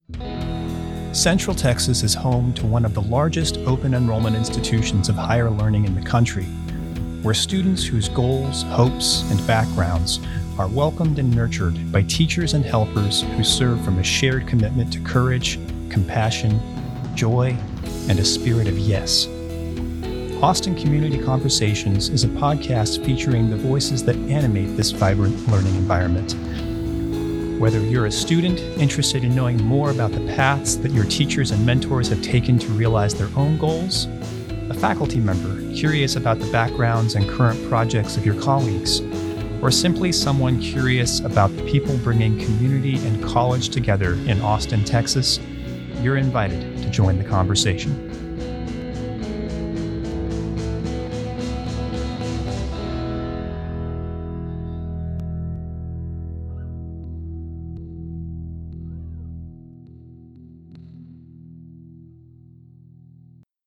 Show trailer